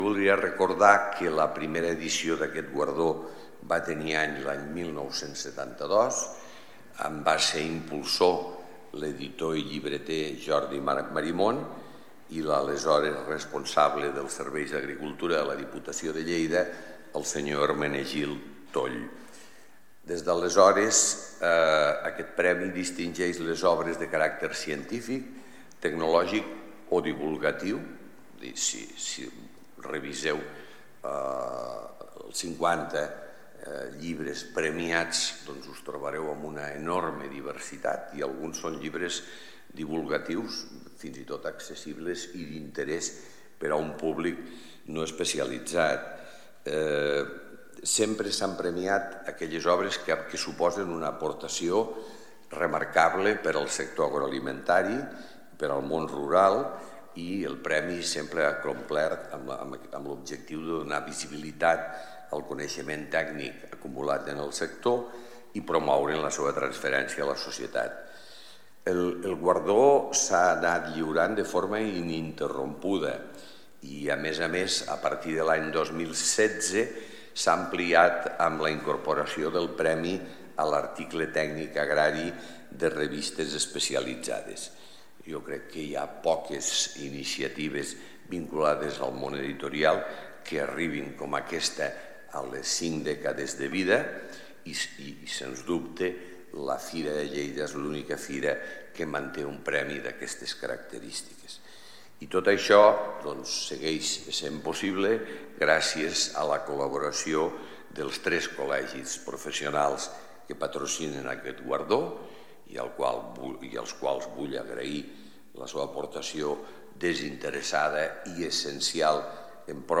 Tall de veu alcalde Miquel Pueyo Fira de Lleida convoca el Premi del Llibre Agrari des de l'any 1972.
tall-de-veu-alcalde-miquel-pueyo-sobre-el-50-aniversari-del-premi-del-llibre-agrari-que-convoca-fira-de-lleida